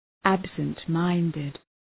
{,æbsənt’maındıd}